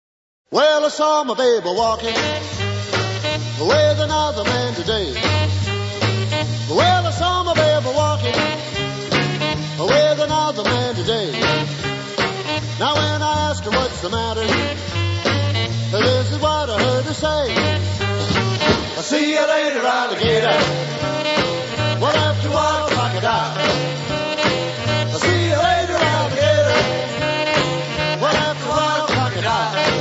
• rock
• rock and roll
• Rock-'n-roll